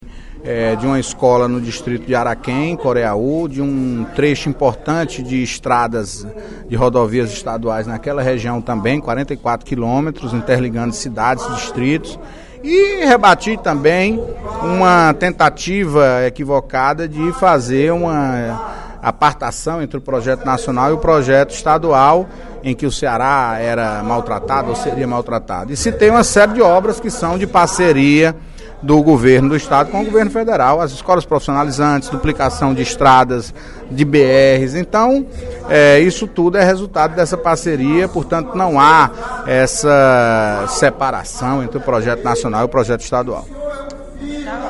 O líder do Governo na Casa, deputado Antonio Carlos (PT), ressaltou na manhã desta sexta-feira (24/02), obras do Executivo Estadual que deverão ser entregues ainda este ano.
Em aparte, o deputado Sérgio Aguiar (PSB) lembrou que o Governo do Estado tem dado “a devida importância a áreas como educação, infraestrutura e segurança”.